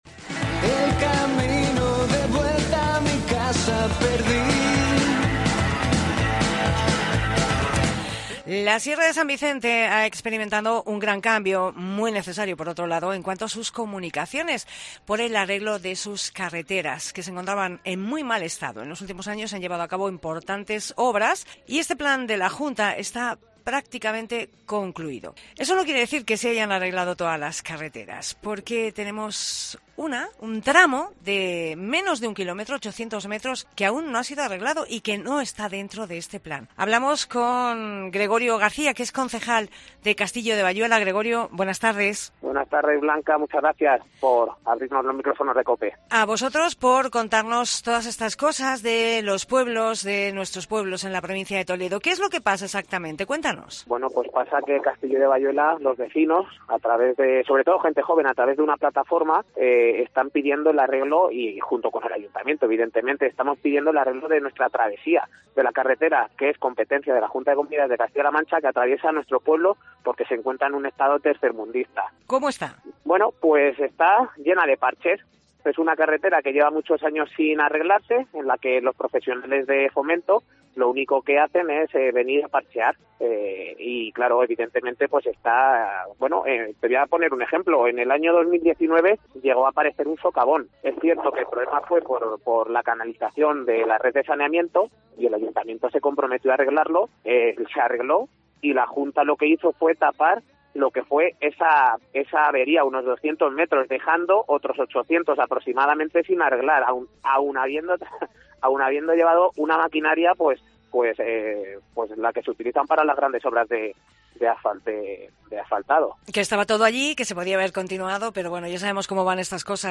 Entrevista a Gregorio García, concejal de Castillo de Bayuela